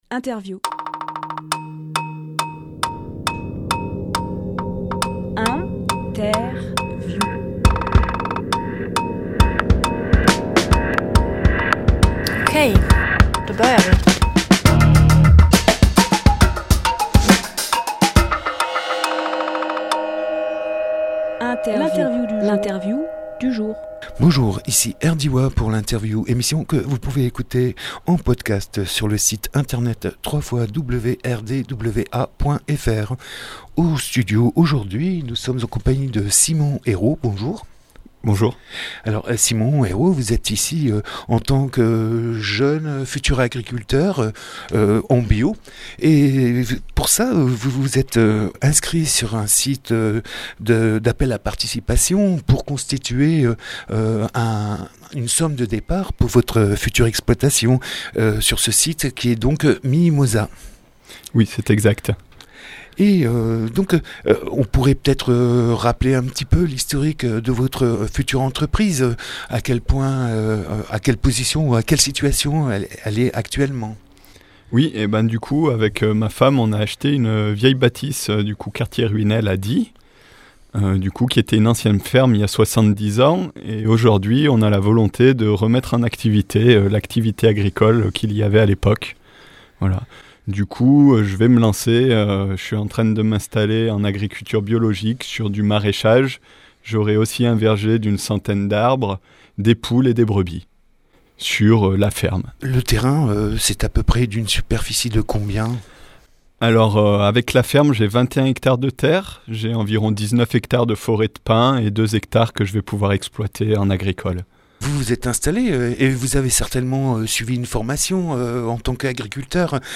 Emission - Interview Financement sur Miimosa pour la ferme de Ruinel Publié le 13 avril 2018 Partager sur…
Lieu : Studio RDWA